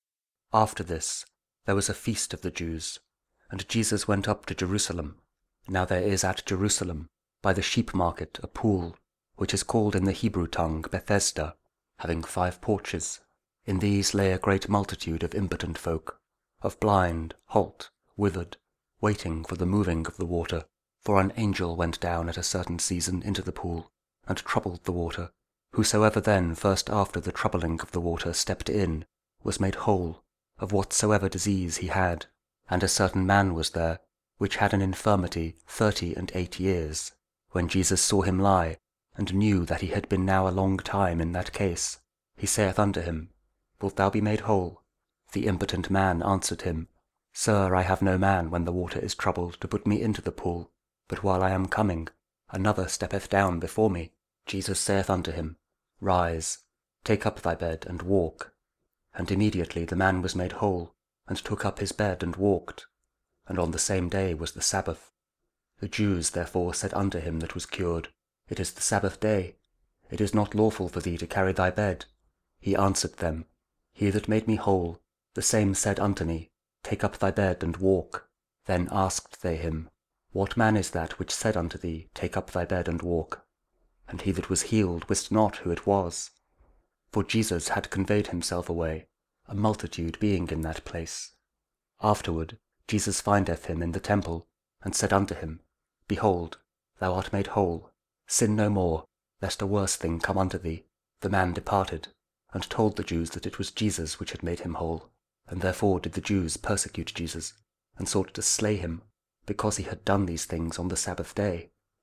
John 5: 1-16 – Lent Week 4, Tuesday (Audio Bible KJV, Spoken Word)